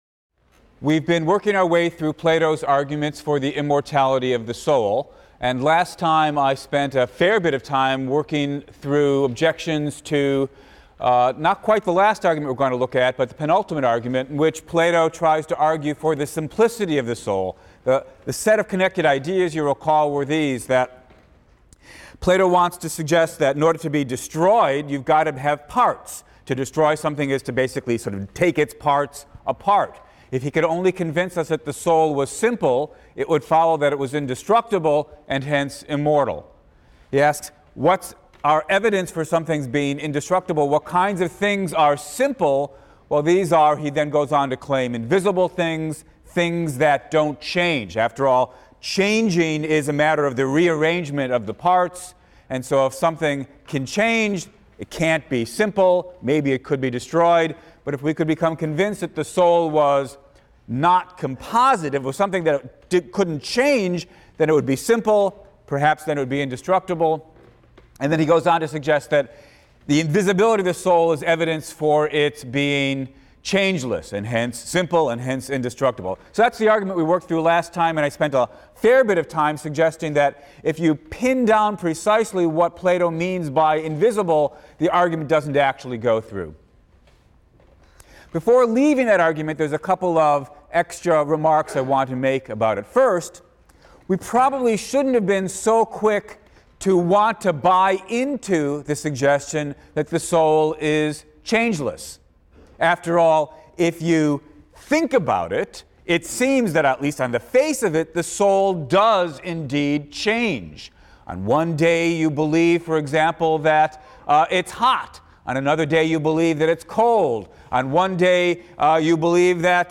PHIL 176 - Lecture 9 - Plato, Part IV: Arguments for the Immortality of the Soul (cont.) | Open Yale Courses